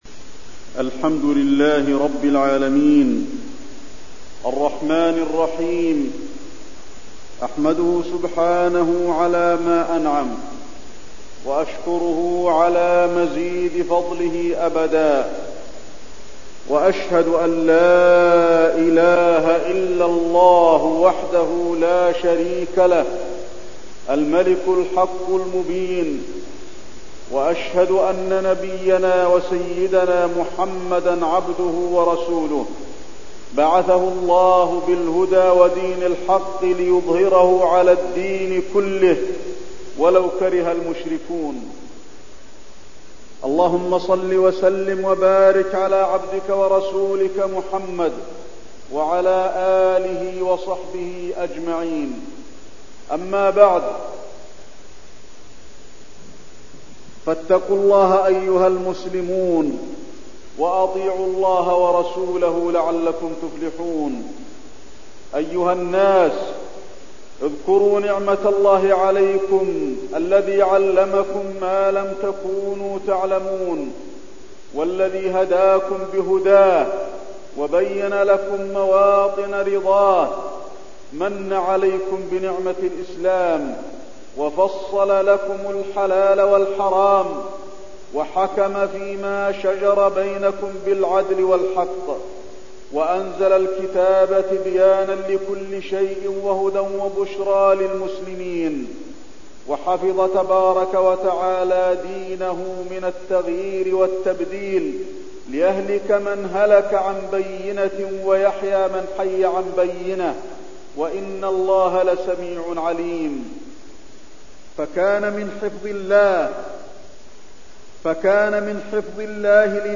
تاريخ النشر ٥ صفر ١٤٠٩ هـ المكان: المسجد النبوي الشيخ: فضيلة الشيخ د. علي بن عبدالرحمن الحذيفي فضيلة الشيخ د. علي بن عبدالرحمن الحذيفي الأمر بالمعروف والنهي عن المنكر